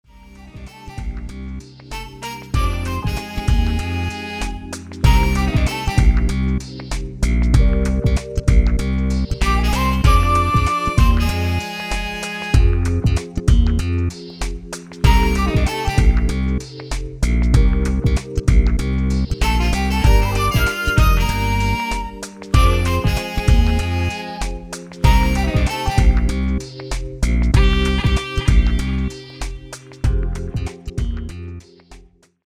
96 BPM